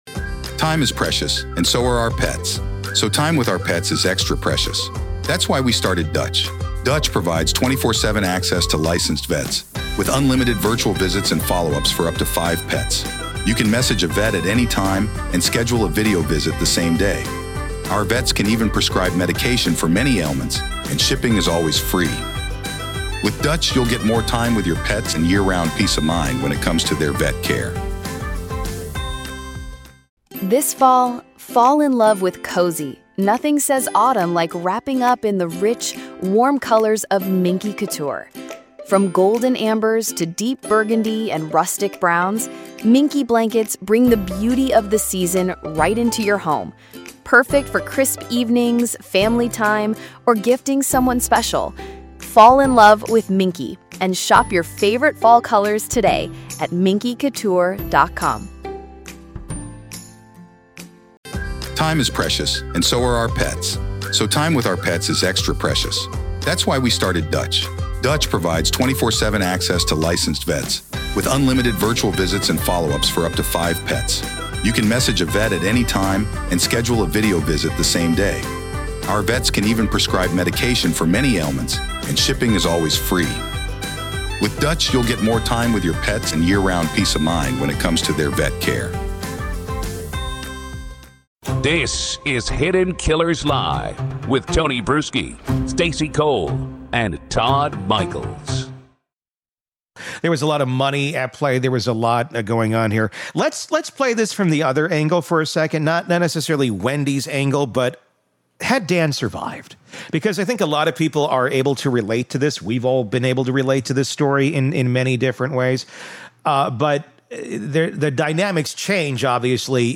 Far from sensational, this conversation is emotionally grounded and painfully honest.